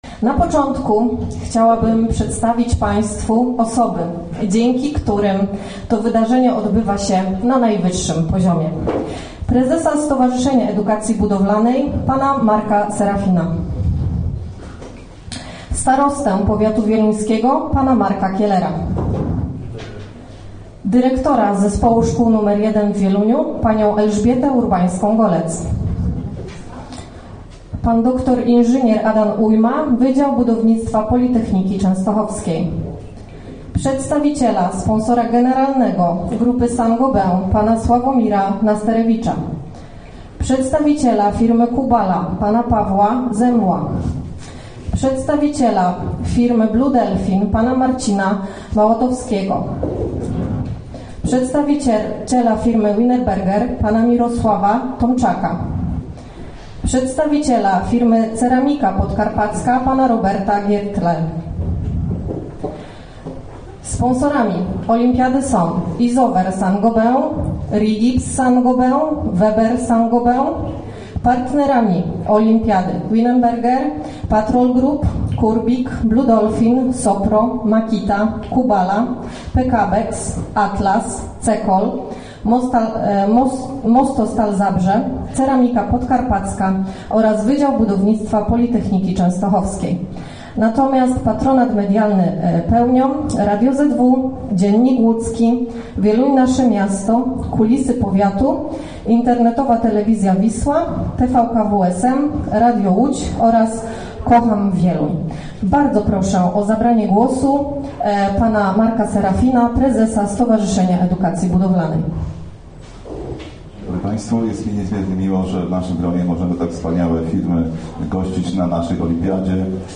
W sobotę zorganizowano konferencję prasową.